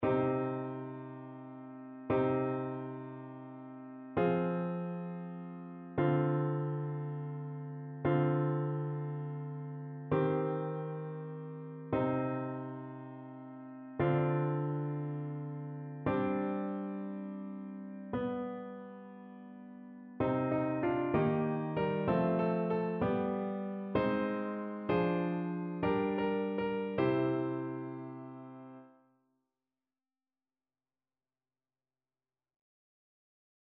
Chœur
annee-abc-fetes-et-solennites-presentation-du-seigneur-psaume-23-satb.mp3